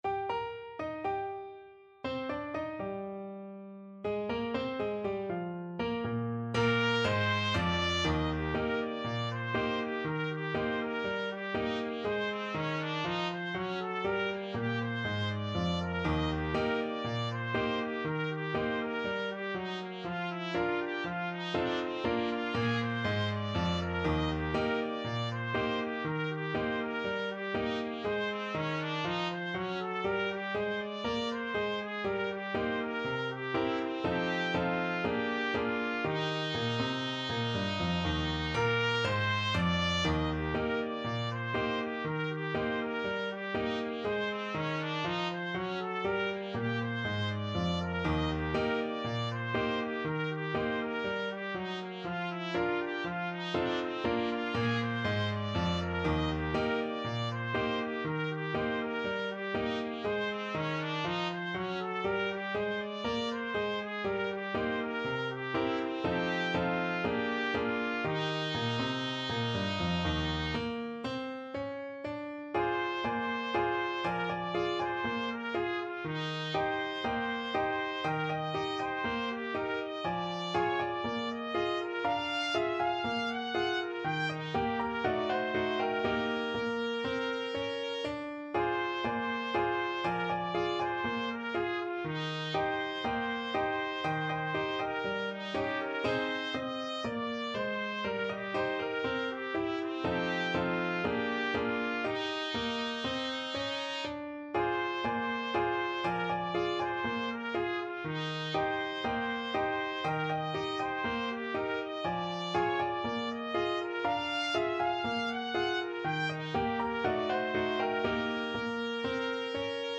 Ab4-Ab6
~ = 60 INTRO Not fast
2/4 (View more 2/4 Music)